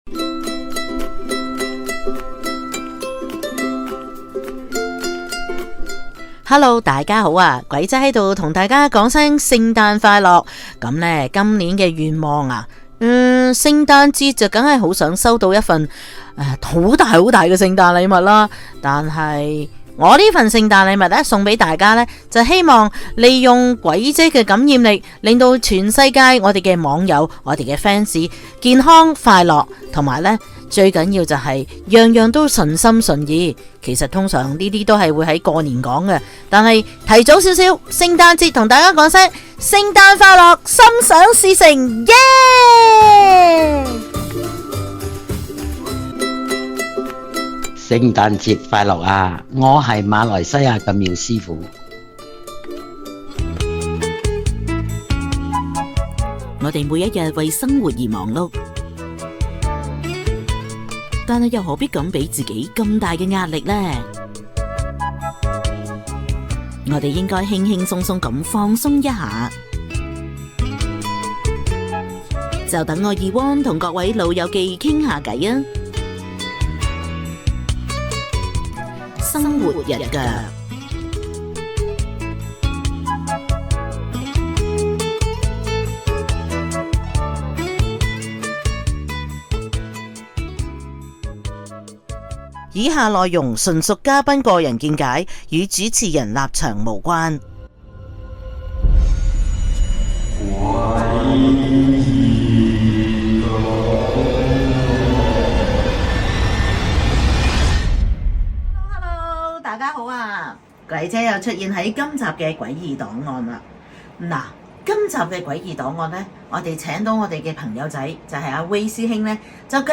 ［生活日腳］鬼異檔案 # phone in報料 # 多倫多又一猛鬼酒店 # 員工常常遇到不可解釋古怪事件 # 網友在博物館內感覺奇怪 # 高靈人士睇吓相中物件有咩問題 2021-12-02